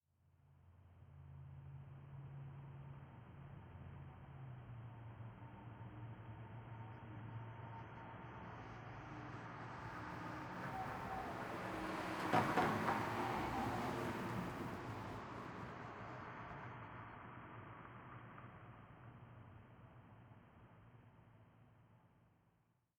1Shot Vehicle Passby with Tire Bumps ST450 02_ambiX.wav